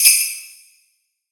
soft-hitfinish.wav